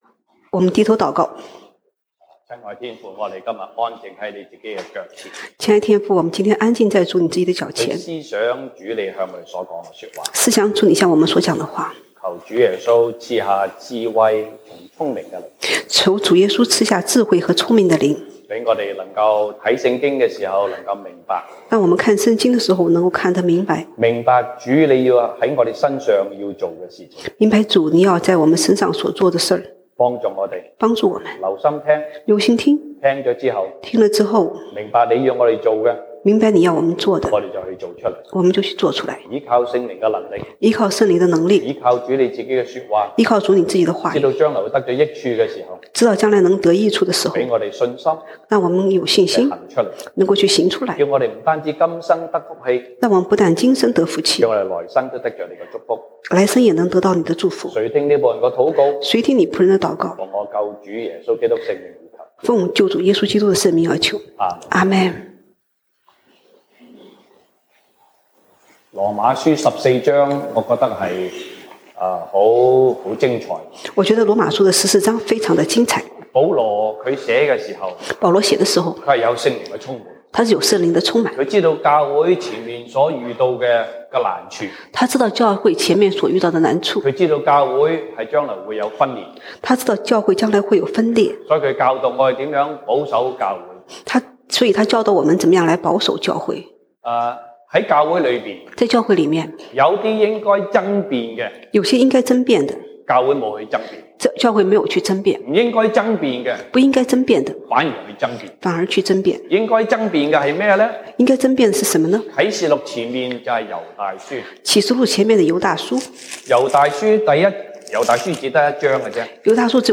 西堂證道(粵語/國語) Sunday Service Chinese: 凡事都是為主